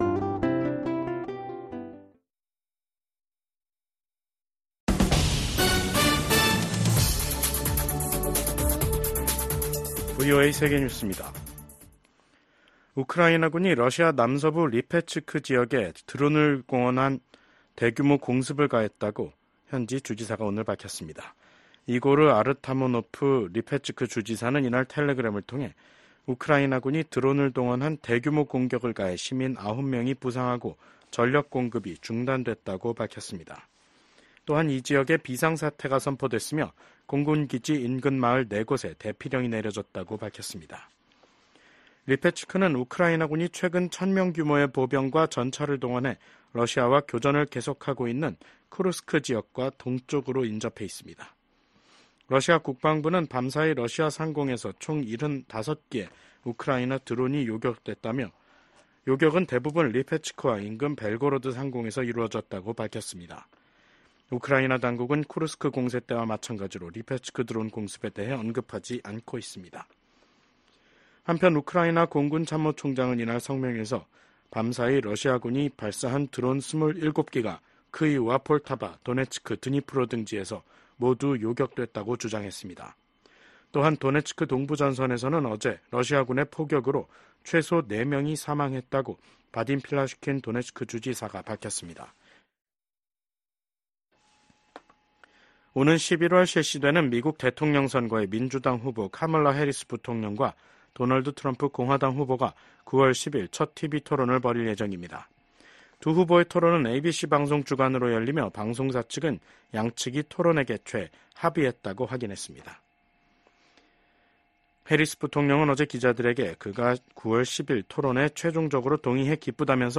VOA 한국어 간판 뉴스 프로그램 '뉴스 투데이', 2024년 8월 9일 2부 방송입니다. 미국 국방부가 신형 미사일 발사대 전방 배치 같은 북한의 행동들은 역내 긴장을 고조시킨다고 지적했습니다. 미국이 한국과 일본 등 역내 동맹과의 협력 강화는 북한 위협 등에 대응하기 위한 방어적 성격이라고 강조했습니다.